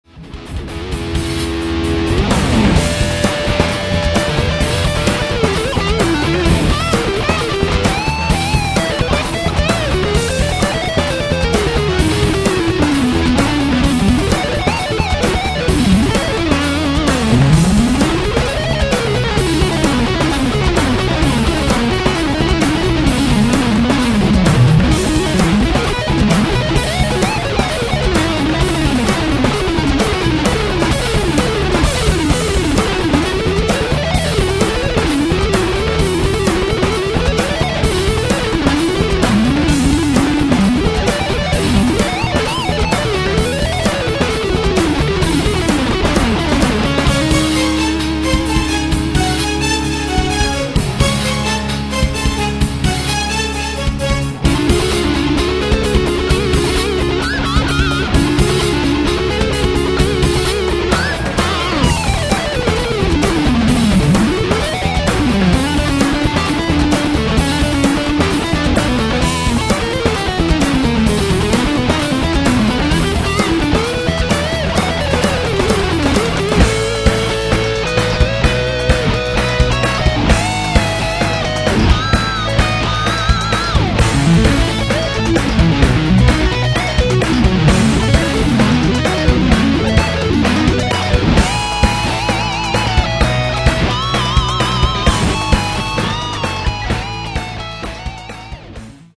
une compo rigolote :